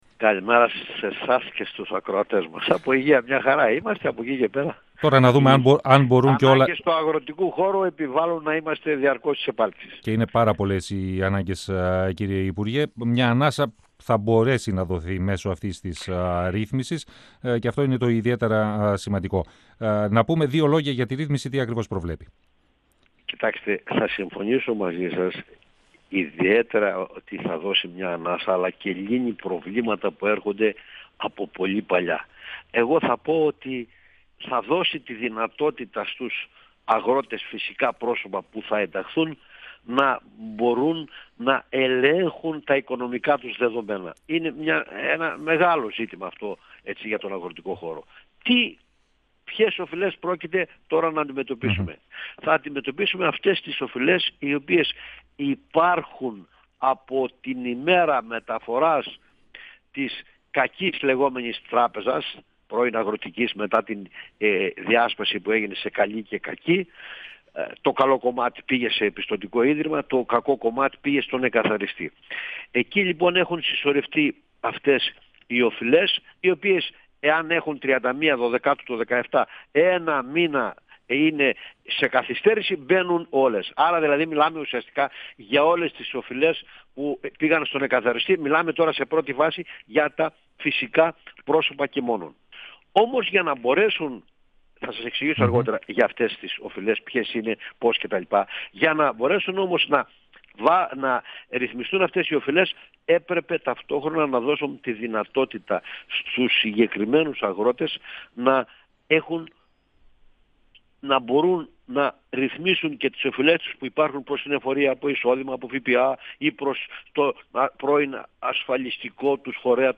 Λύση σε προβλήματα που χρονίζουν θα δώσει η ρύθμιση των αγροτικών χρεών, επισήμανε ο υπουργός Αγροτικής Ανάπτυξης Βαγγέλης Αποστόλου, μιλώντας στον 102FM του ραδιοφωνικού Σταθμού Μακεδονίας της ΕΡΤ3.
Συνέντευξη